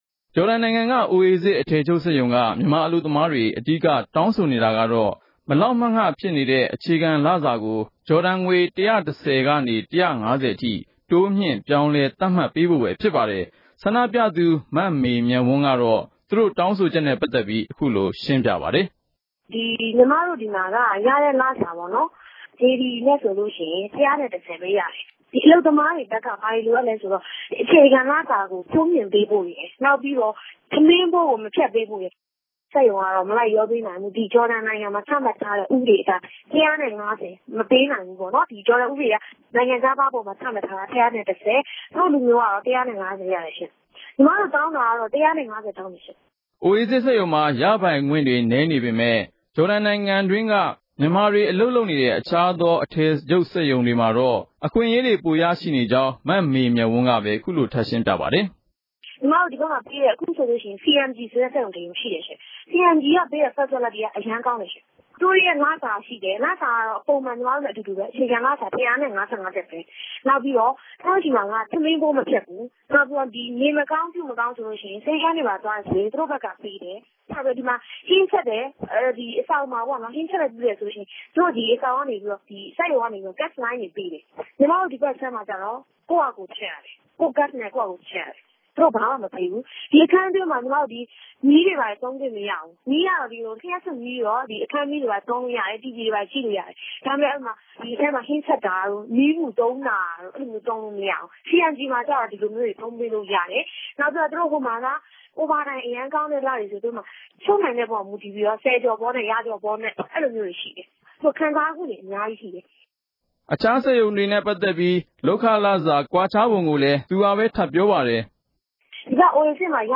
မေးမြန်းတင်ပြချက်။